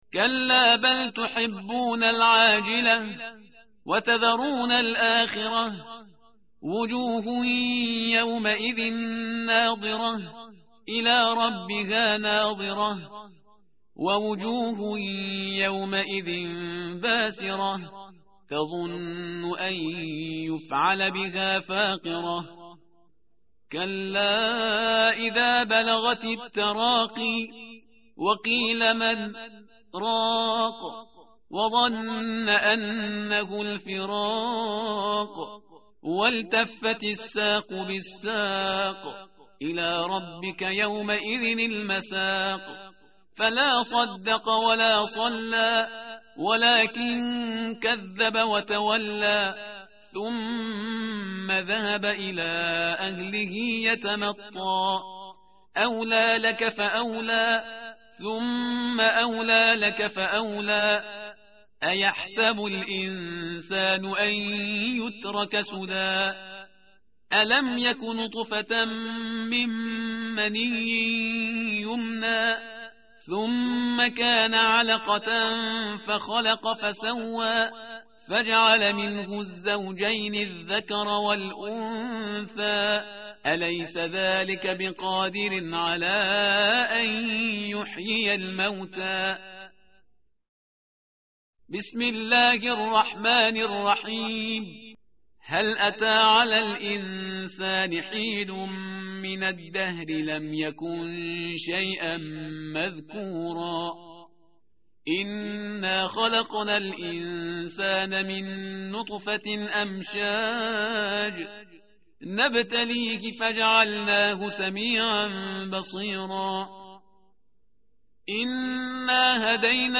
tartil_parhizgar_page_578.mp3